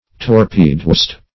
What does torpedoist mean?
Search Result for " torpedoist" : The Collaborative International Dictionary of English v.0.48: Torpedoist \Tor*pe"do*ist\, n. (Nav.)
torpedoist.mp3